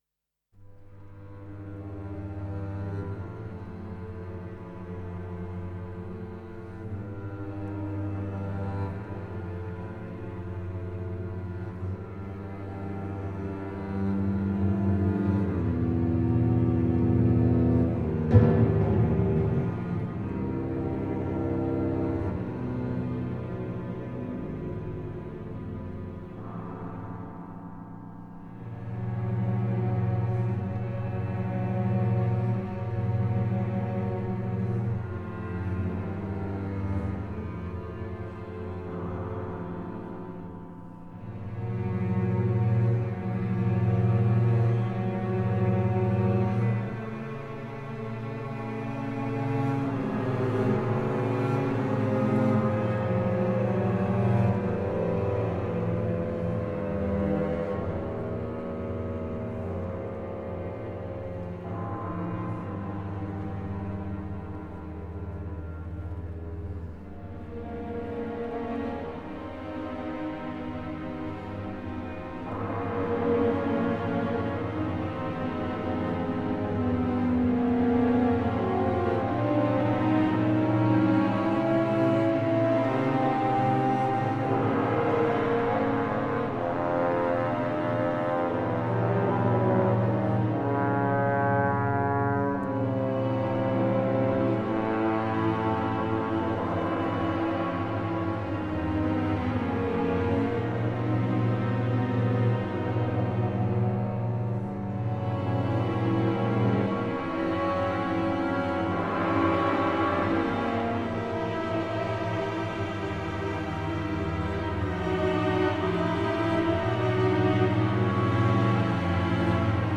симфония